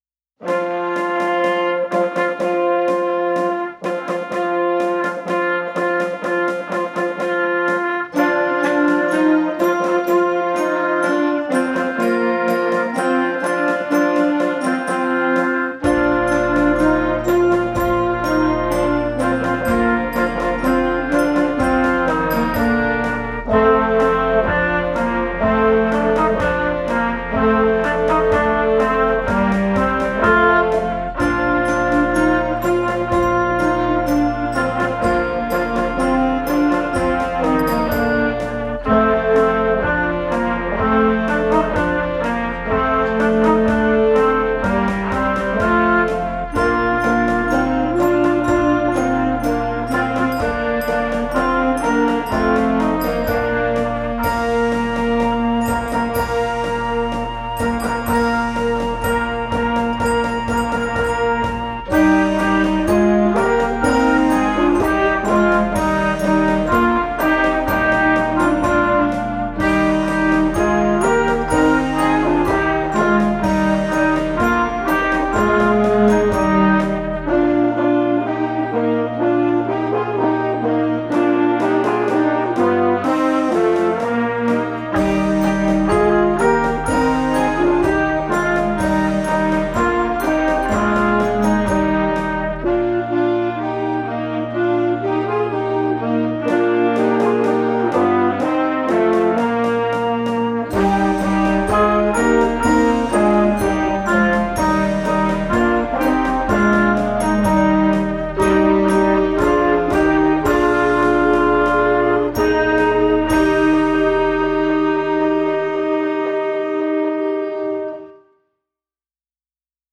敲擊樂組
為了讓團員感受到合奏，本校利用電腦為同學製作合成樂曲（影片及聲帶）敲擊樂組